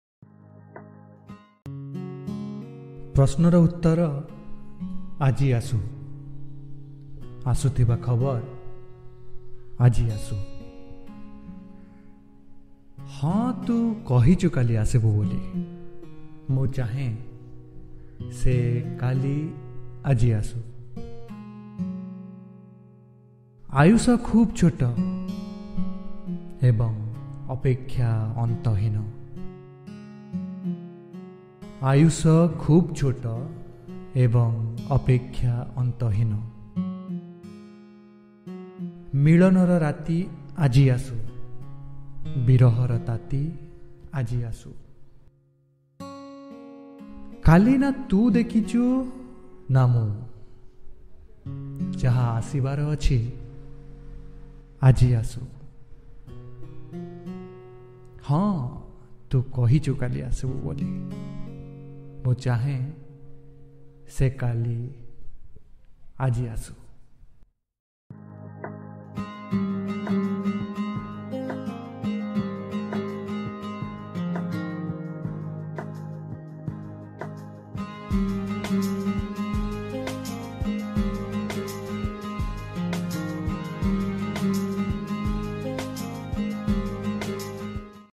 Odia Poem